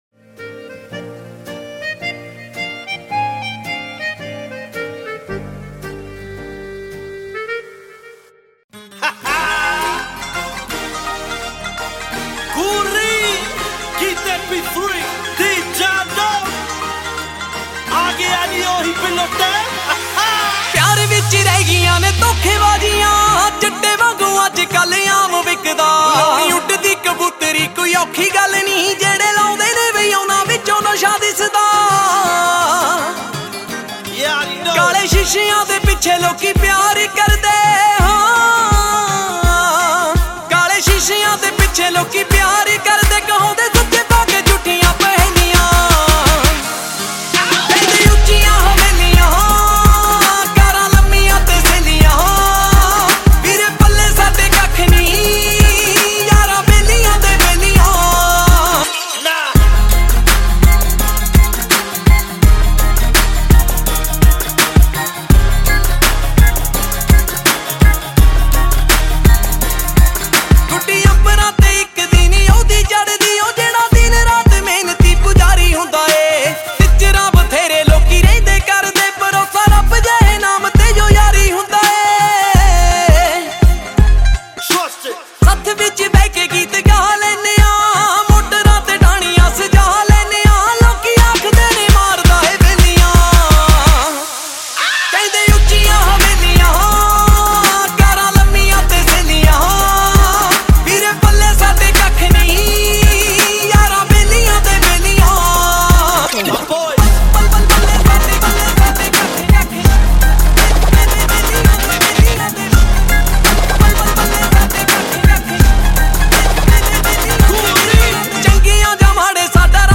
Latest Punjabi Songs